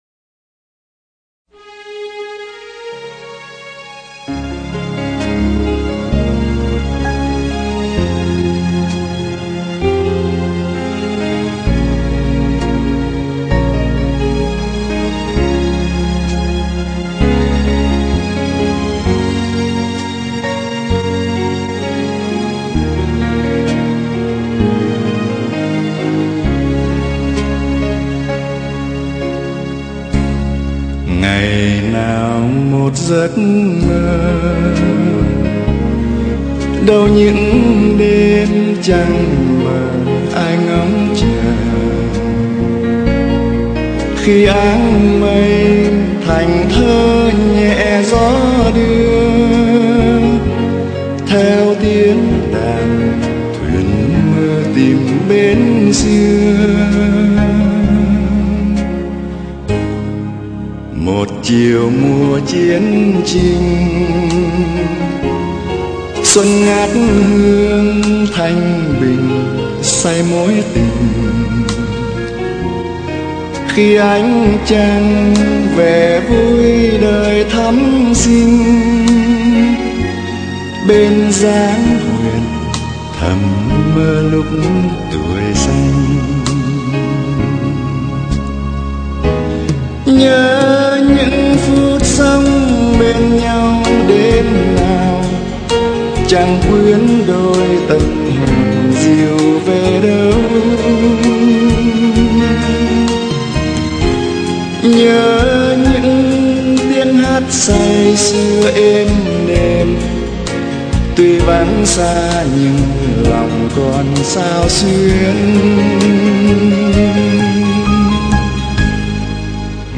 Trình Bày: Sĩ Phú
Chúng tôi sẽ cố sưu tập bản nhạc được hát bởi một ca sĩ miền Nam trước 1975 để ý nghĩa bảo tồn được trọn vẹn, dù rằng cũng bản nhạc đó, với phần kỹ thuật, phối âm, phối khí và ca sĩ trẻ hơn thực hiện tại hải ngọai sau này có hay hơn nhiều.